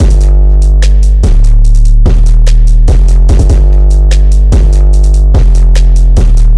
标签： 失真 男声 人声
声道立体声